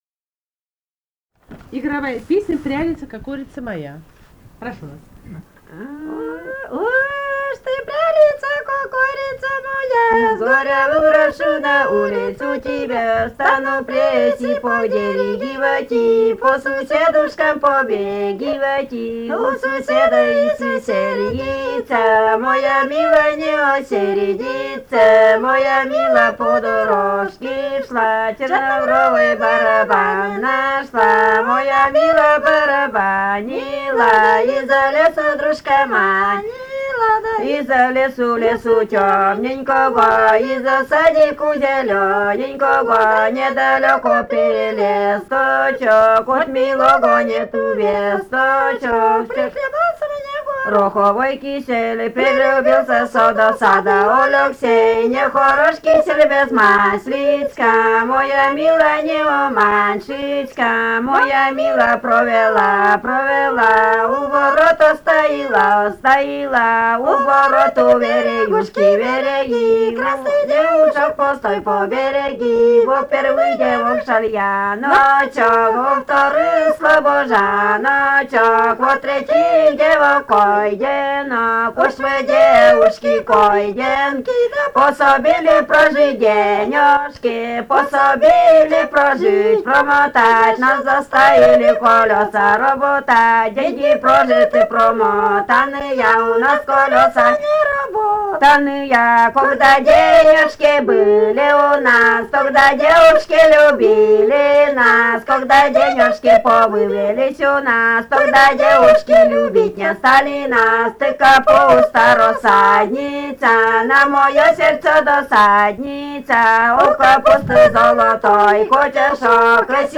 Архангельская область, с. Долгощелье Мезенского района, 1965, 1966 гг.